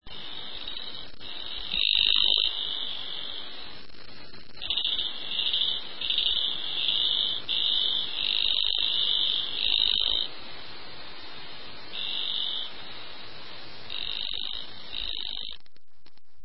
Bilingual Frog, Ratchet Frog calling